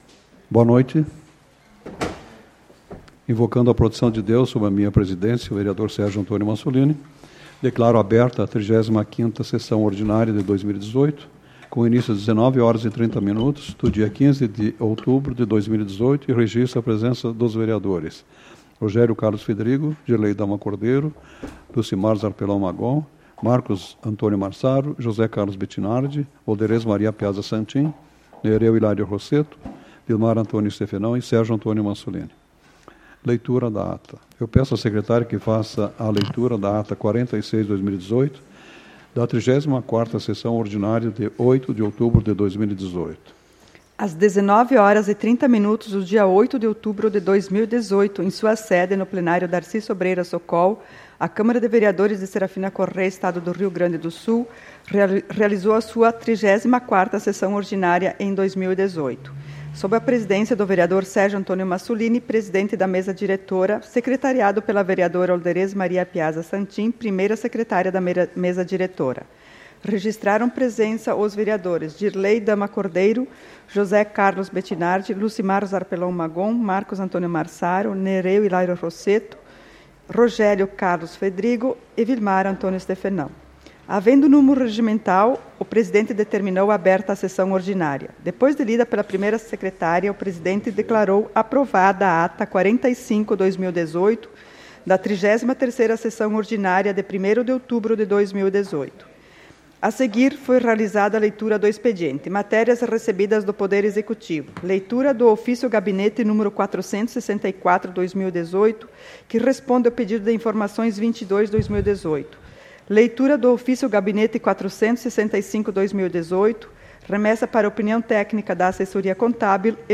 Leitura da Ata nº 46/2018, da 34ª Sessão Ordinária, de 15 de outubro de 2018.